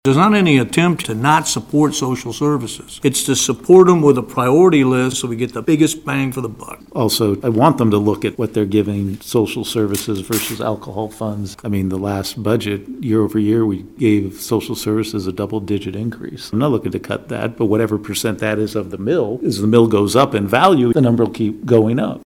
Commissioners Wynn Butler and John Matta disagreed and shared their reasoning.